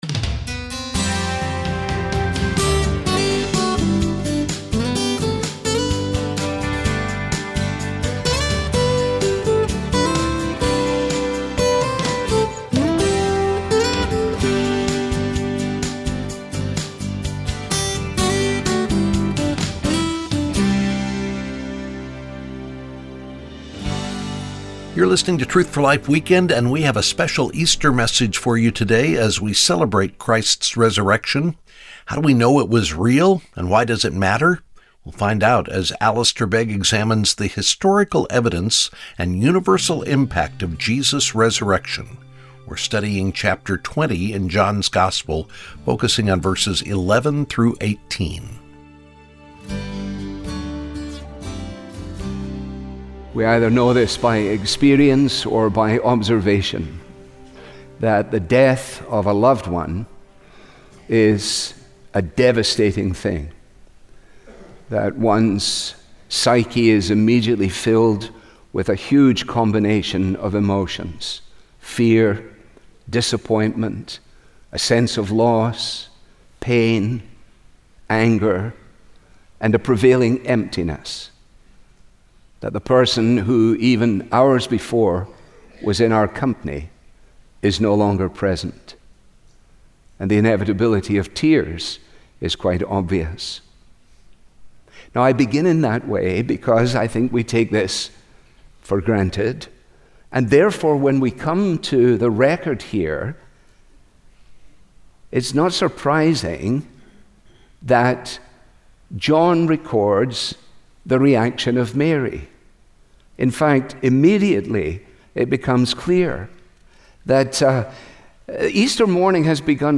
• This program is part of a special sermon ‘Why Are You Weeping?’